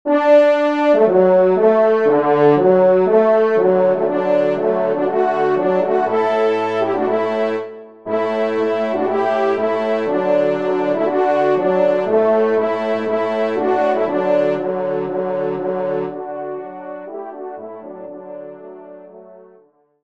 Genre :  Musique Religieuse pour Trois Trompes ou Cors
ENSEMBLE